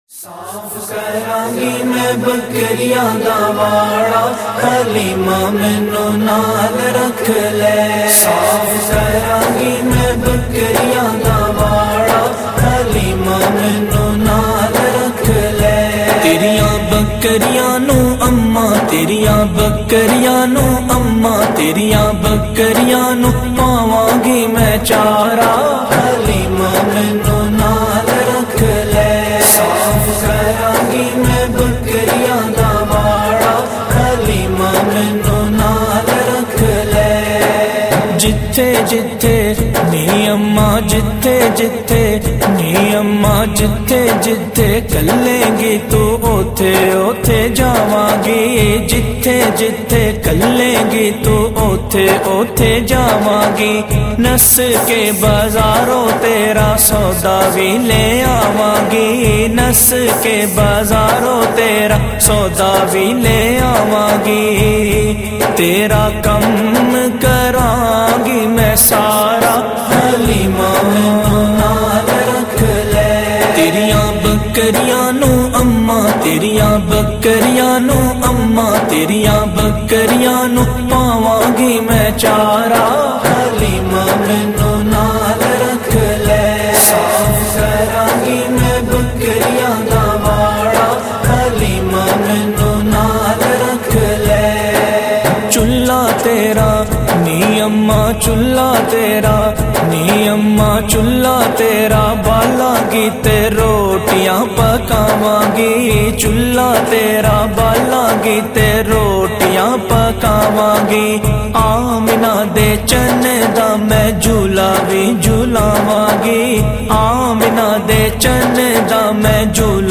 punjabi naat sharif